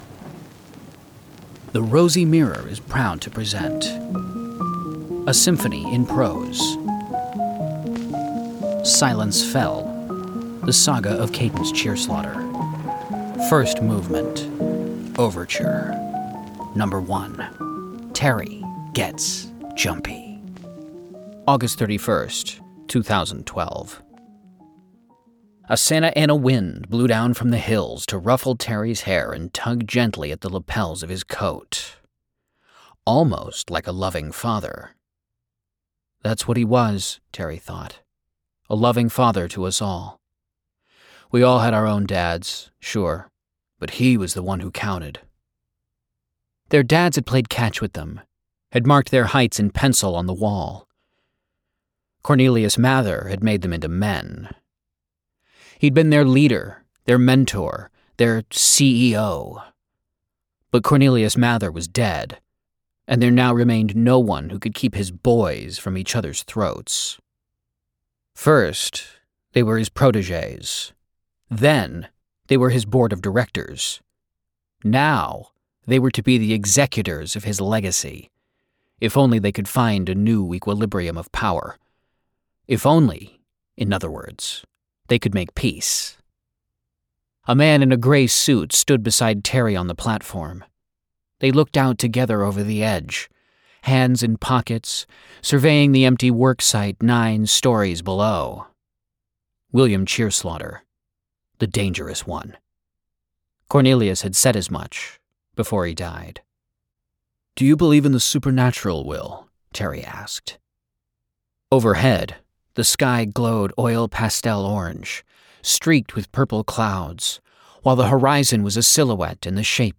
The Rosy Mirror: neo-noir paranormal audio fiction.